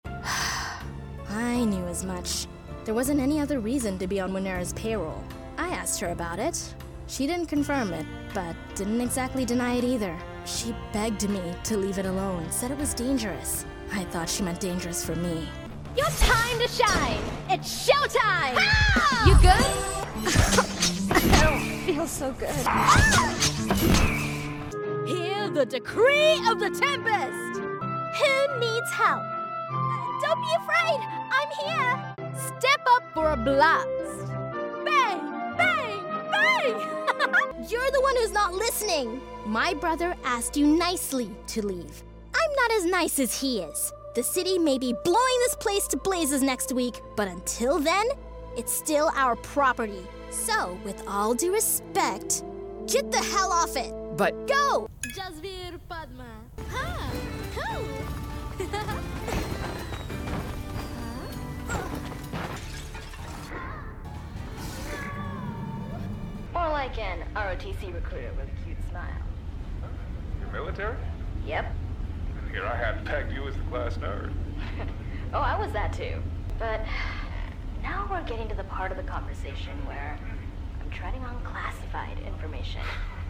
GAMING 🎮
VideoGameReel.mp3